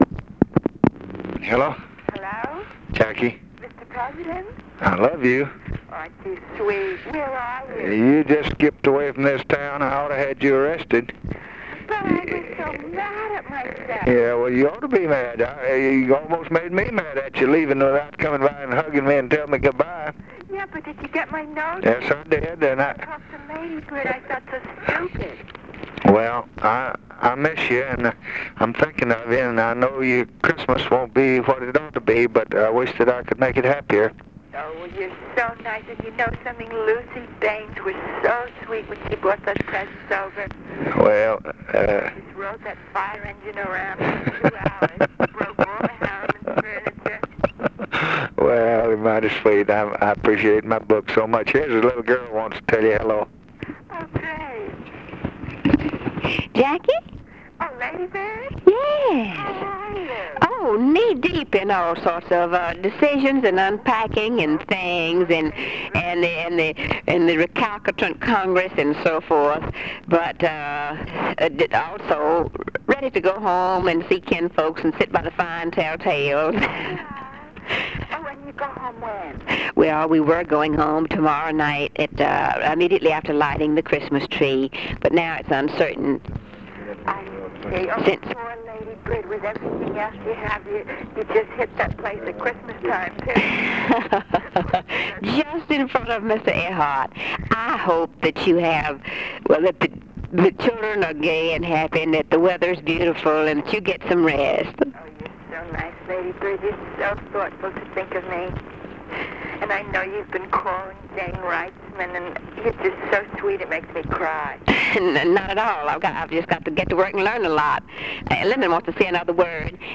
Conversation with JACQUELINE KENNEDY, December 21, 1963
Secret White House Tapes